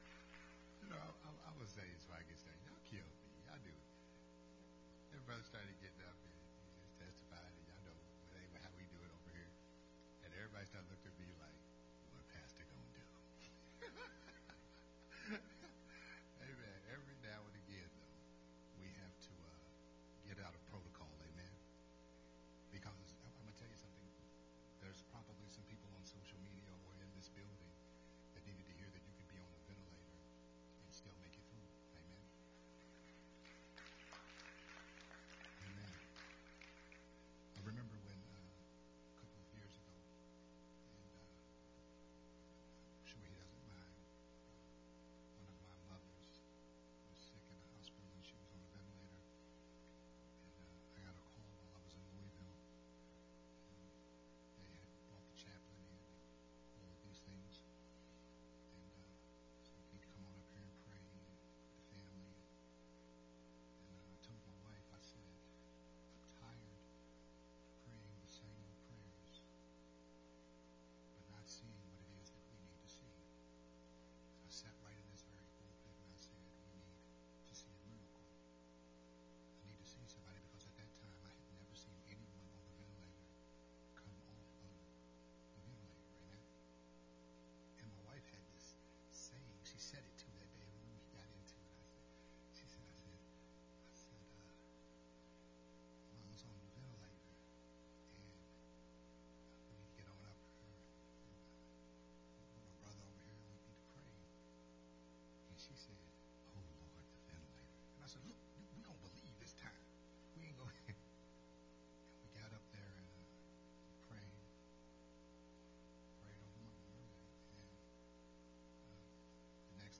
Part 1 of the new sermon series